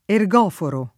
[ er g0 foro ]